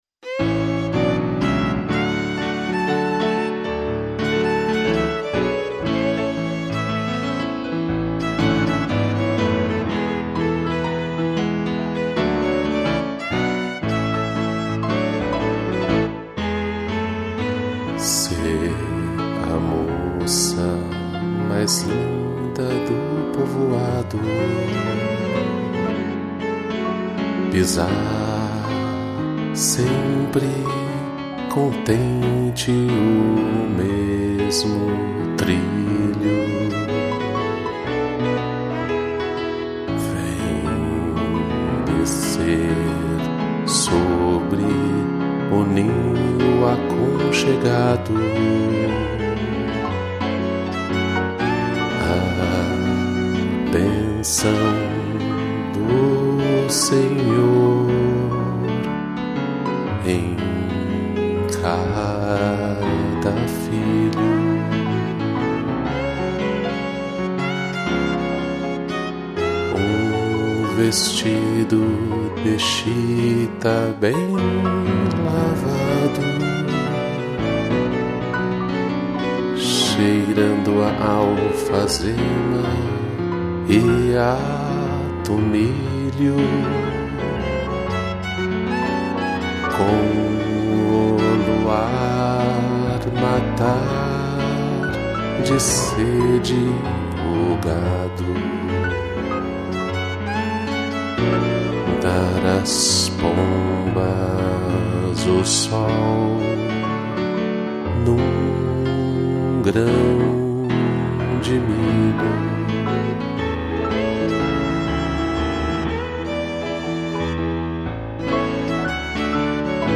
2 pianos e violino